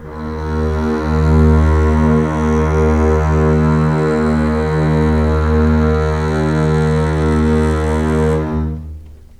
D#2 LEG MF R.wav